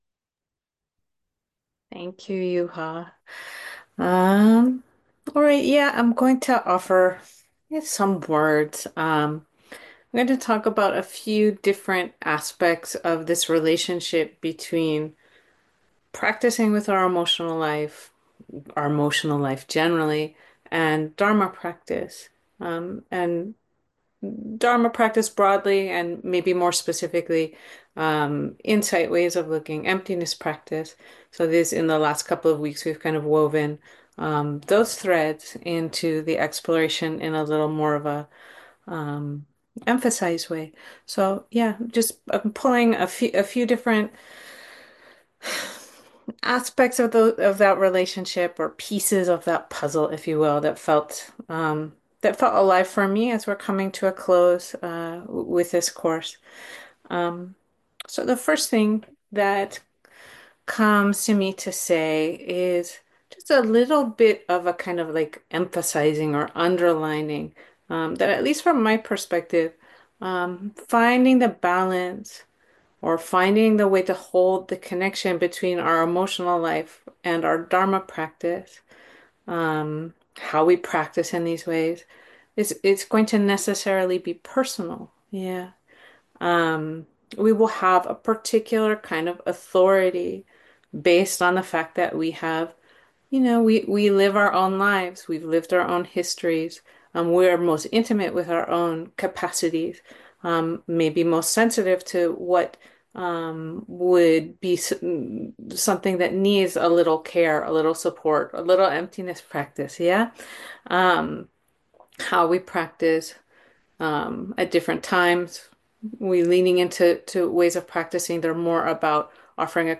dharma_talk_week5_emotions_course.mp3